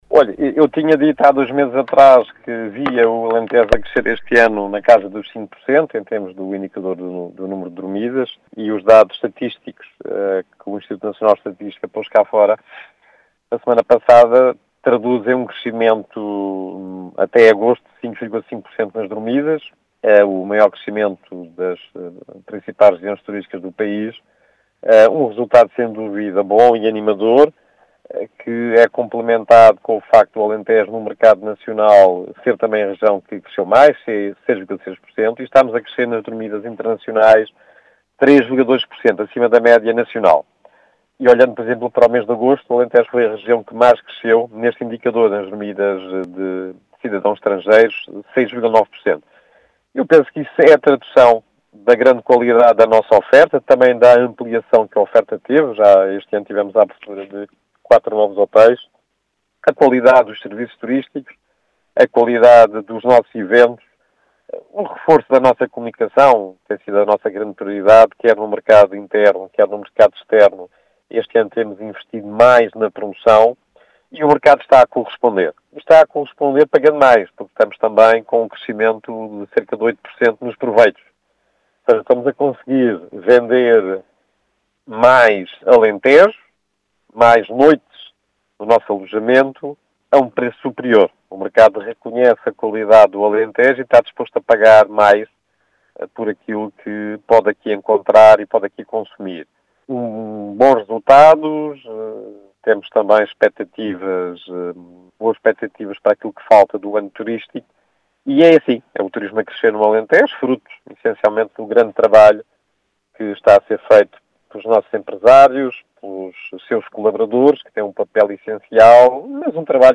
Em declarações à Rádio Vidigueira, José Santos, Presidente do Turismo do Alentejo, justifica estes resultados com a “grande qualidade da oferta” do destino, fruto do “grande trabalho” que está a ser realizado por todos os intervenientes na região.